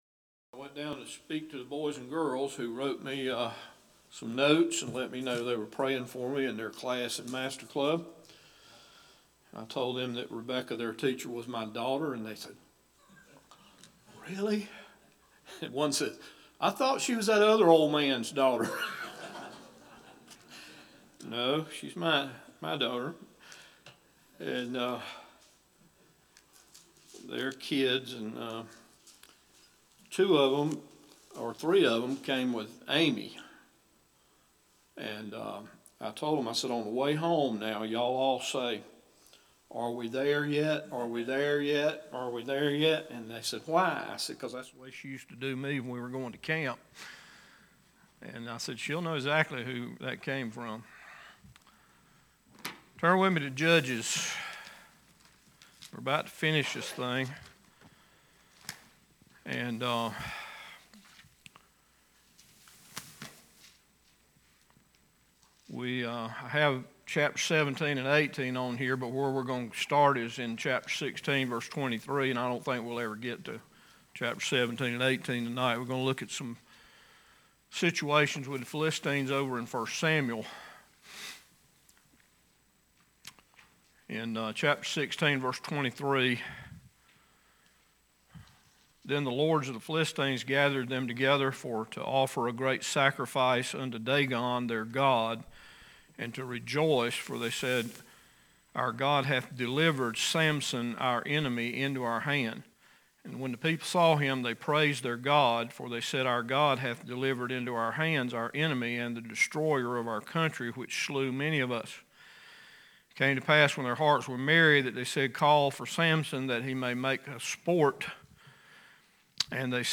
Judges Bible Study 12 – Bible Baptist Church